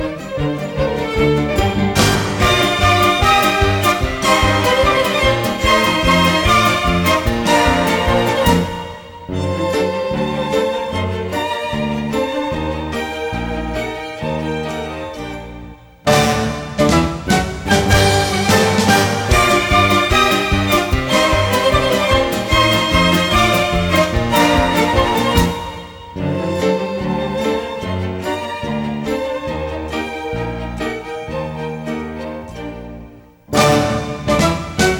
# Smooth Jazz